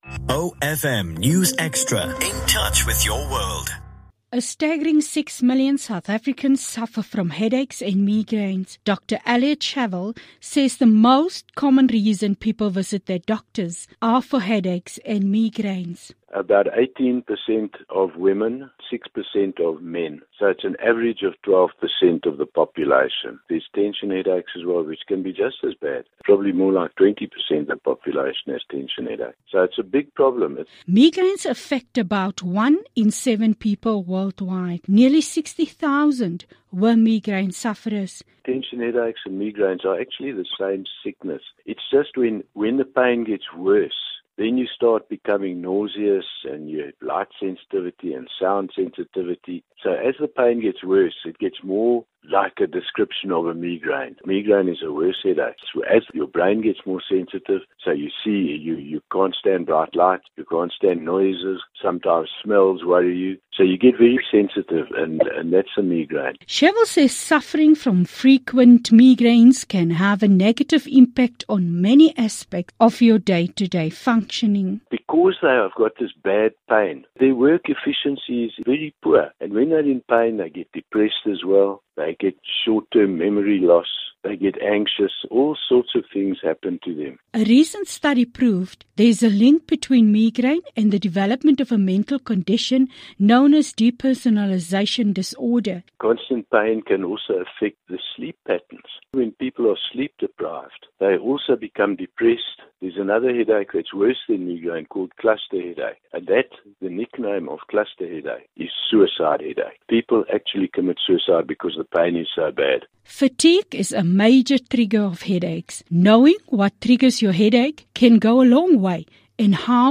Headache and migraine awareness week interview | The Headache Clinic
news_20_sep_10am_feature_headaches_and_migraine_awareness_high.mp3